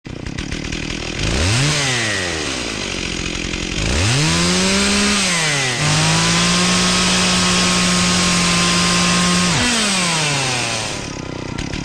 Звуки бензопилы
Зловещий звук бензопилы из фильма ужасов